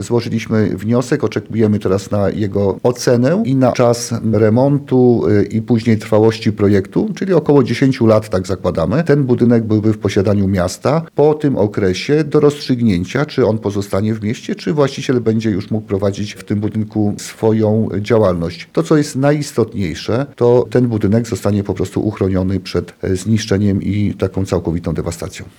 O szczegółach mówi burmistrz Tomaszowa Lubelskiego, Wojciech Żukowski.